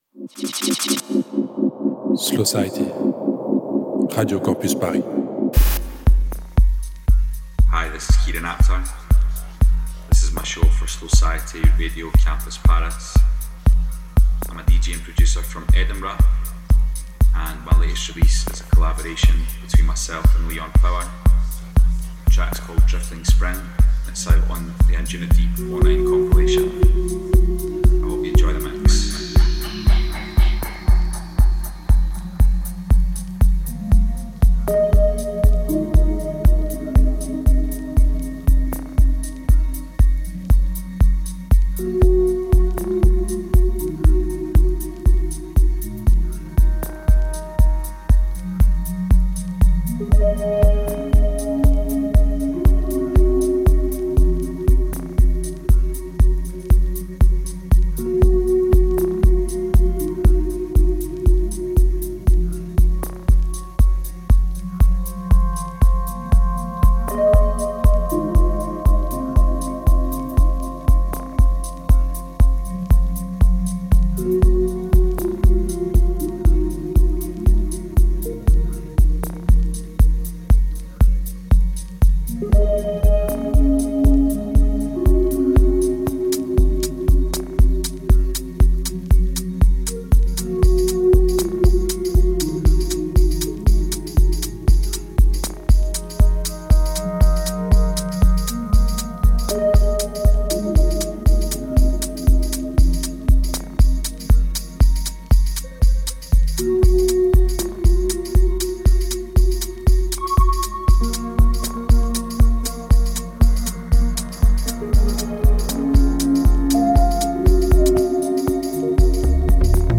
une heure de house mélancolique